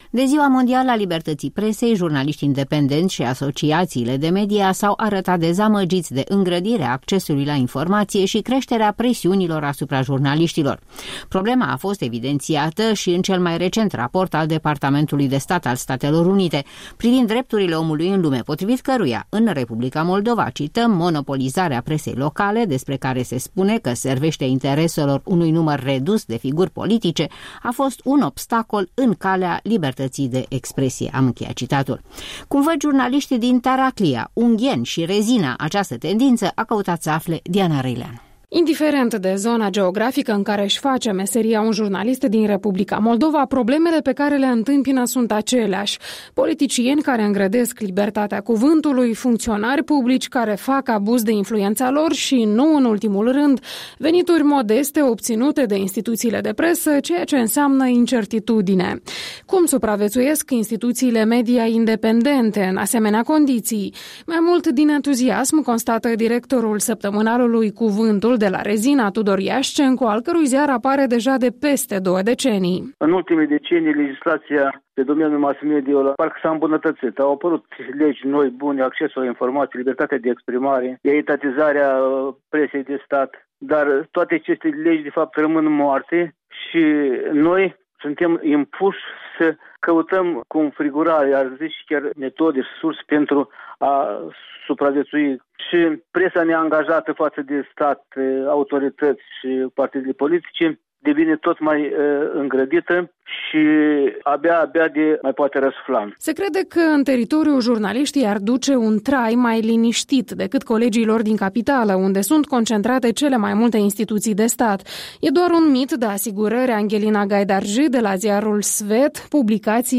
De vorbă de Ziua Internațională a Libertății Presei cu redactorii șefi ai unor publicații moldovene din provincie.